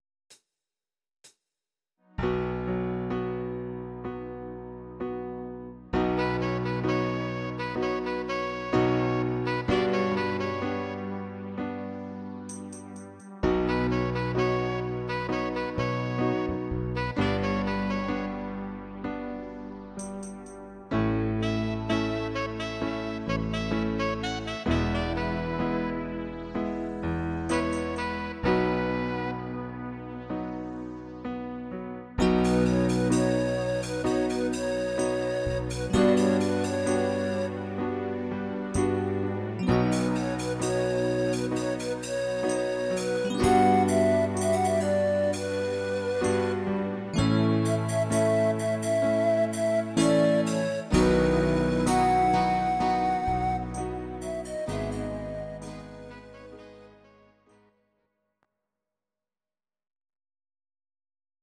Audio Recordings based on Midi-files
Ital/French/Span, Duets, 2000s